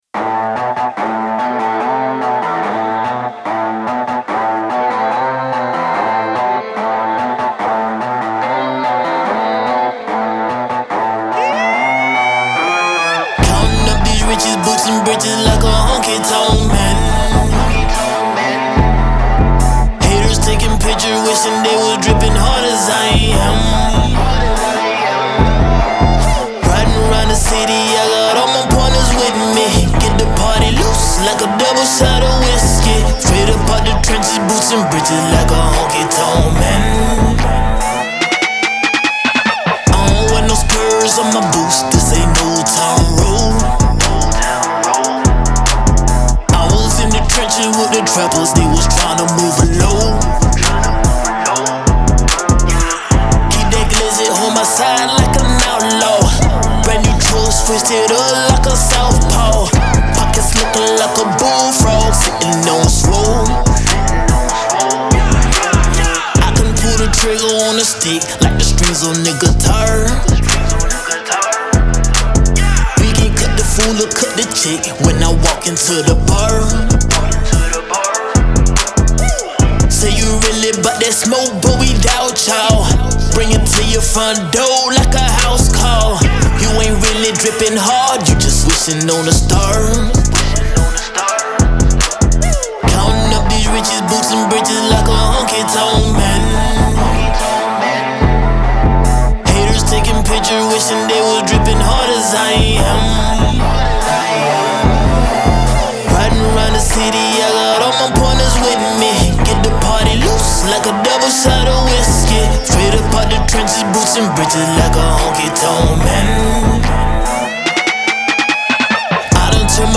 Trap
Honky tonk trap anthem